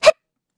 Xerah-Vox_Attack1_kr.wav